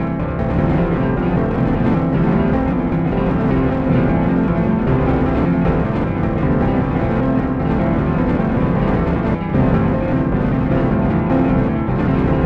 And a few crazy loops, mostly done with random kits and functions.
Blip_PianoCascade.wav